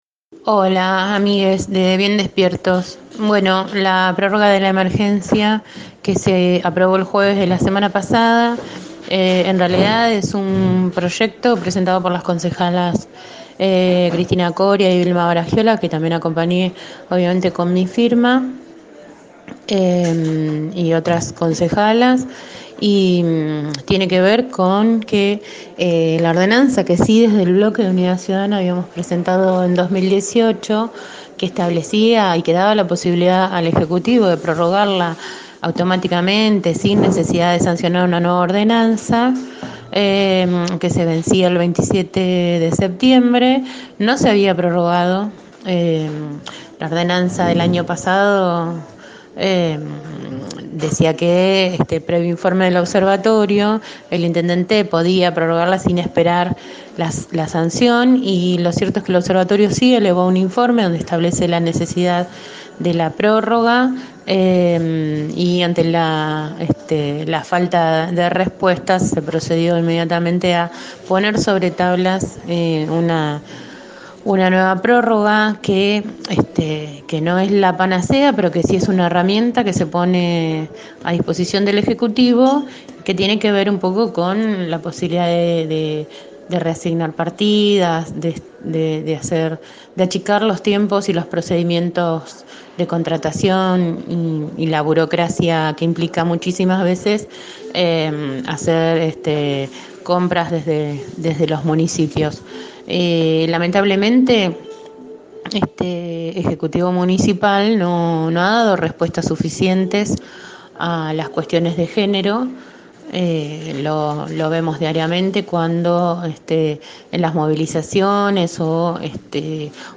Verónica Lagos, concejala de Unidad Ciudadana, habló esta mañana en el programa radial Bien Despiertos, producido de lunes a viernes de 7:00 a 9:00 por De la Azotea 88.7,  sobre la prórroga de la emergencia en violencia de género y  la falta de respuestas del gobierno municipal.